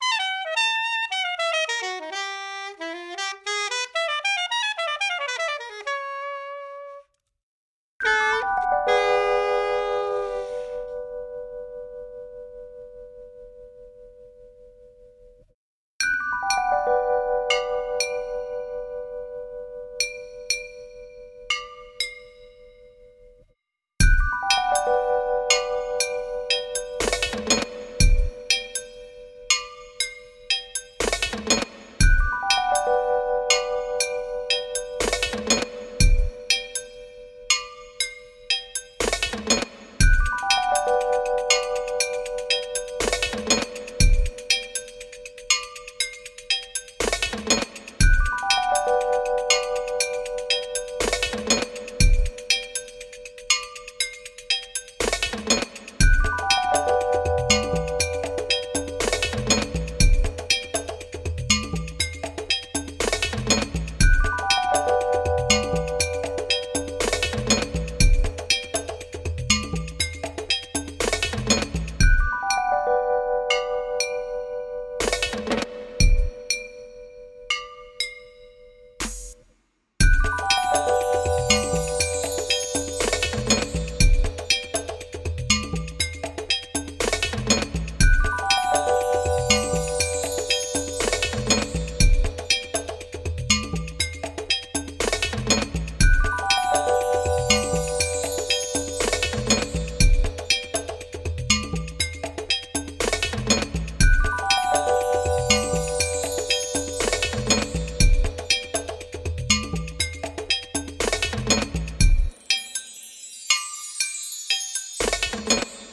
Pack from 1 to 5 [LOW quality].